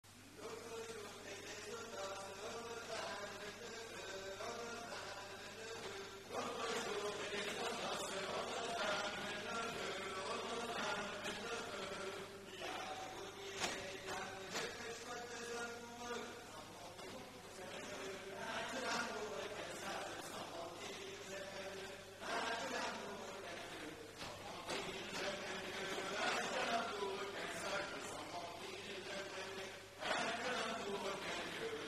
Chansons en dansant
danse : ronde : rond de l'Île d'Yeu
Pièce musicale inédite